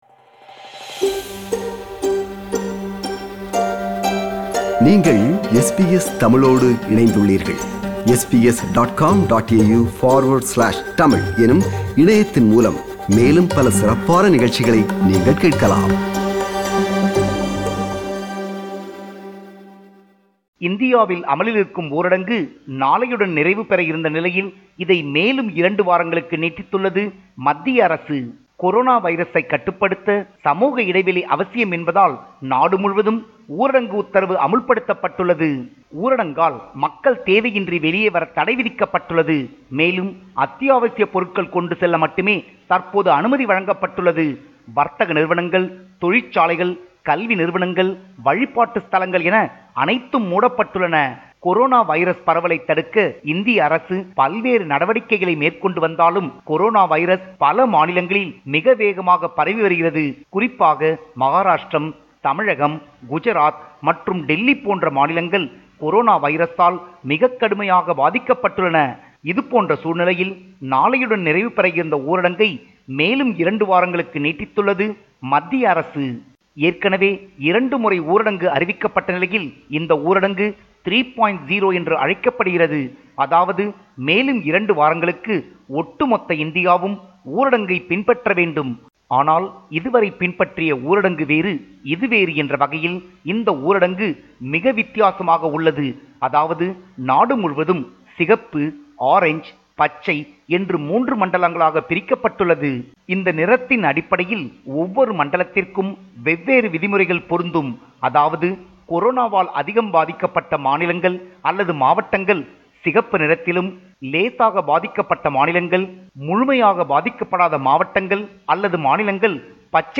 Tamil Nadu report